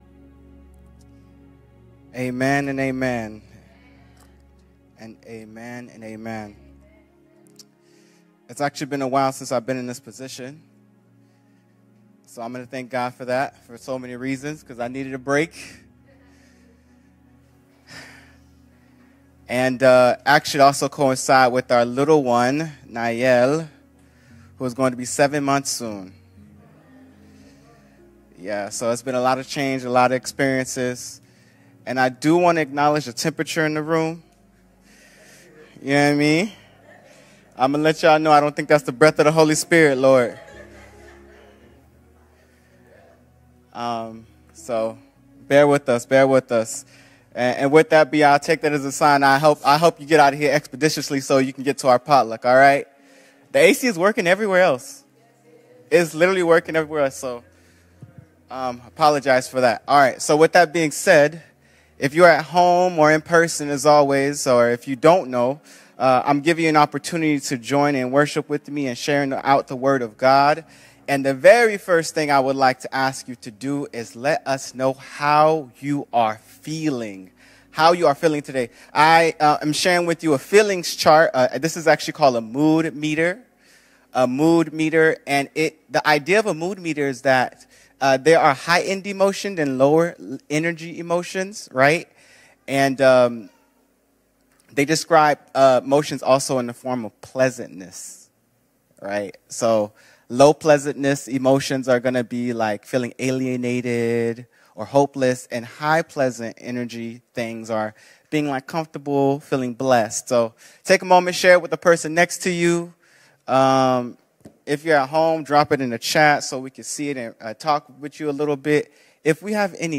Sermons | Message of Hope SDA Community Mission